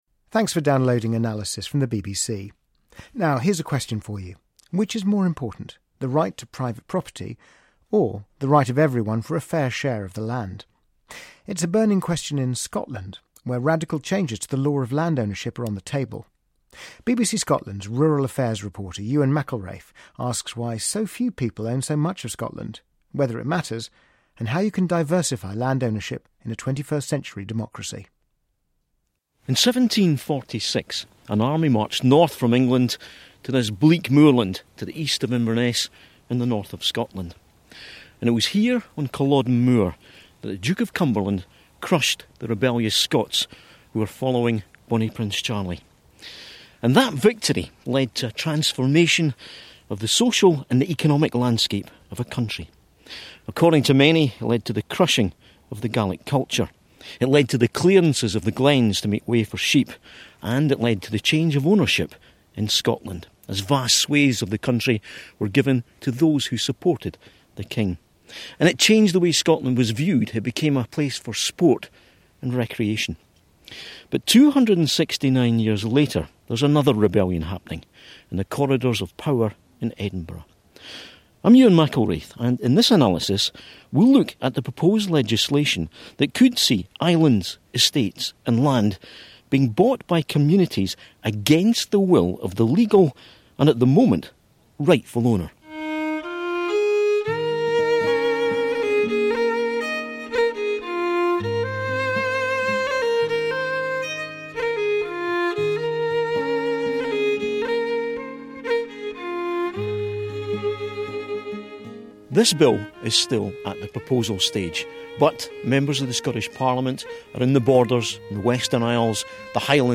First broadcast on BBC Analysis on October 18, 2015